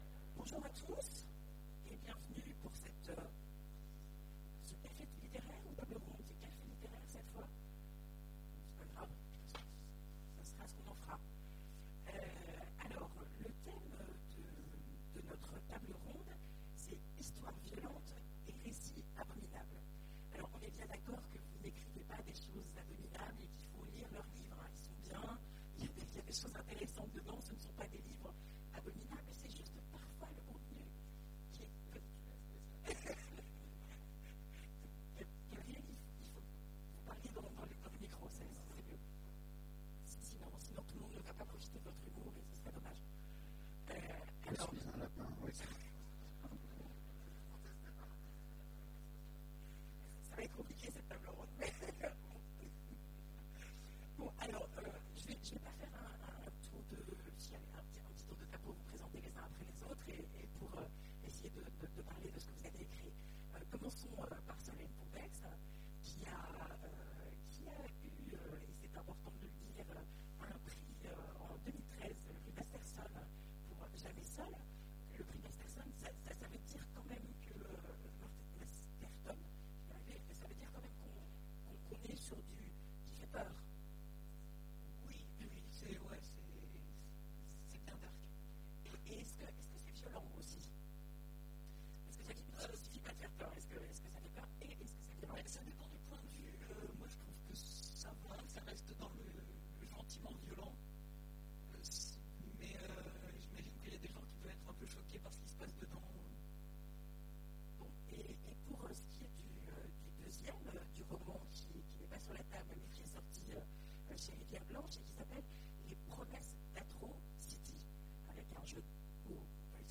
Imaginales 2015 : Conférence Histoires violentes...